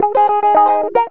GTR 6 A#M110.wav